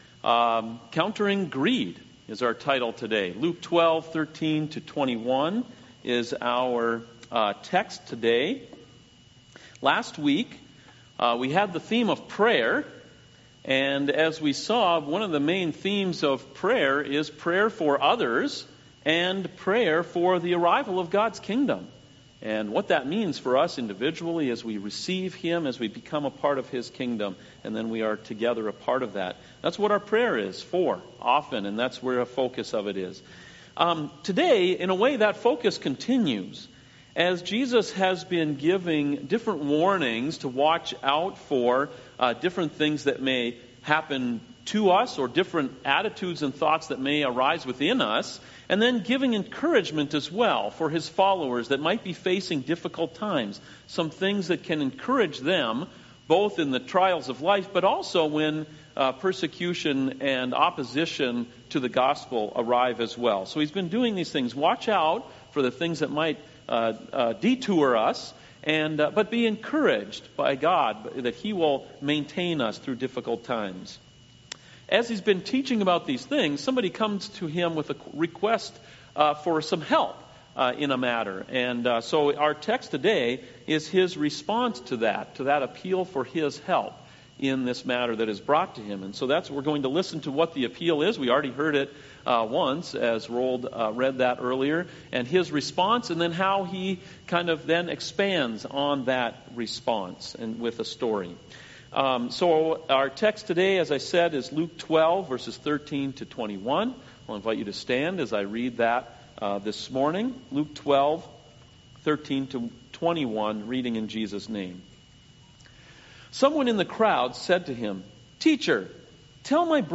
CoJ Sermons Countering Greed (Luke 12:13-21)